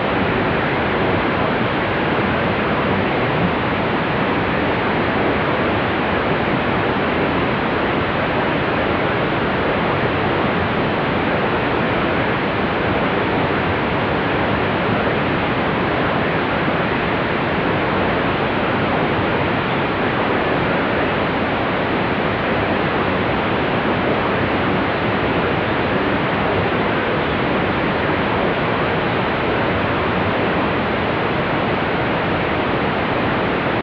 Bass/Nobass test @ -6 dB SNR This test compares a highpass cutoff of 60 Hz and 250 Hz at a poor S/N ratio of -6dB.